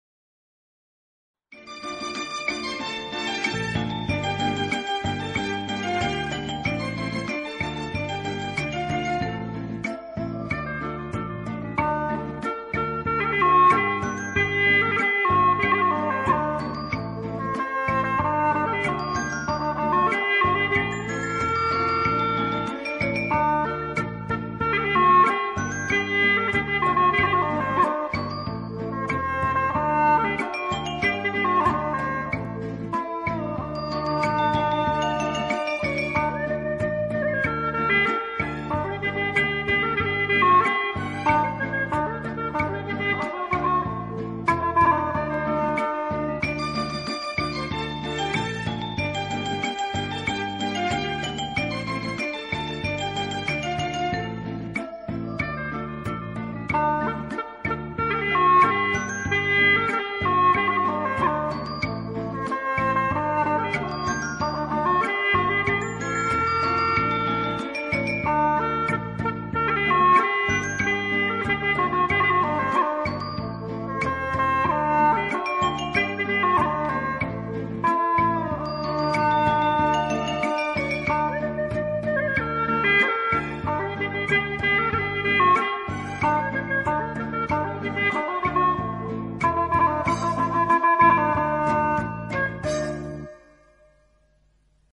调式 : F 曲类 : 少儿